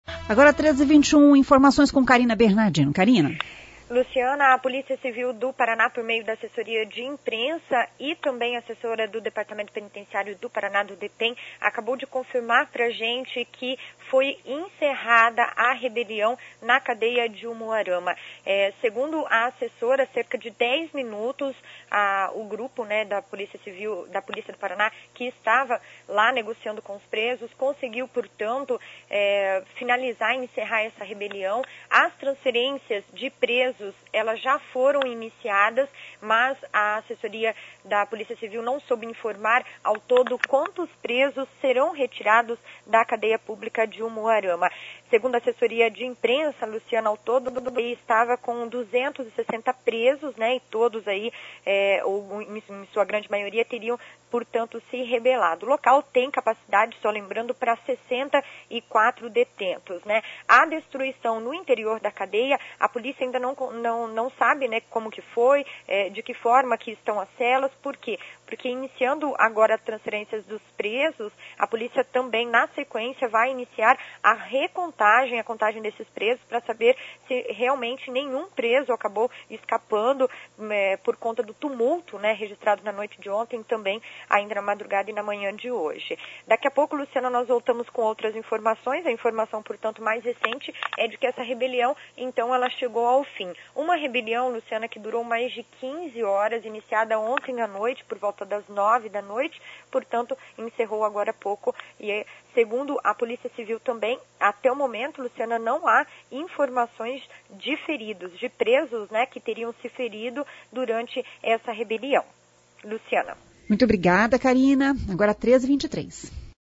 Nota termino da rebelião C.B 28-09 AO VIVO.mp3